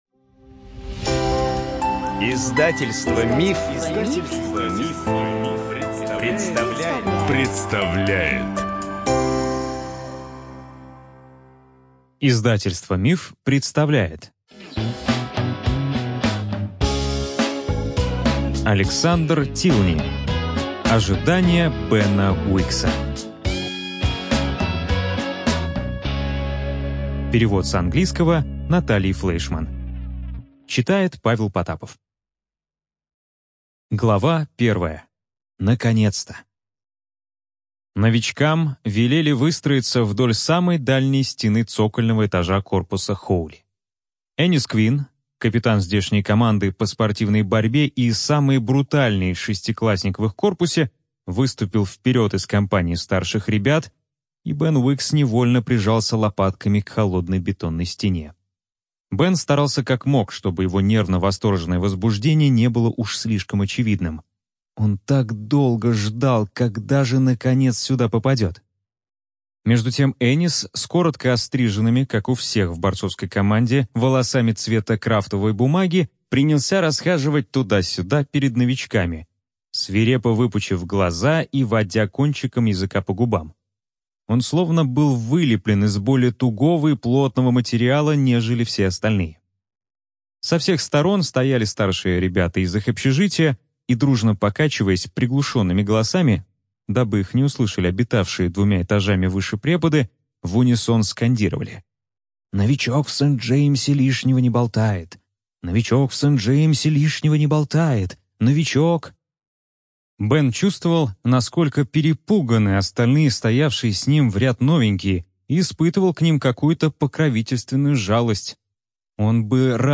ЖанрСовременная проза
Студия звукозаписиМанн, Иванов и Фербер (МИФ)